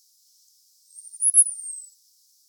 Calypte_costae_song.mp3